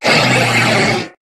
Cri de Lougaroc dans sa forme Crépusculaire dans Pokémon HOME.
Cri_0745_Crépusculaire_HOME.ogg